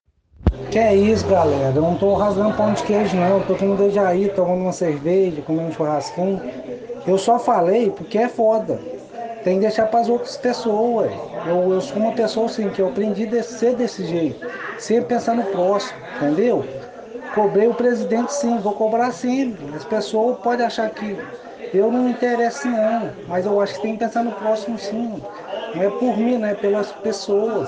Com a palavra, o Vereador Canela Love, ouça abaixo.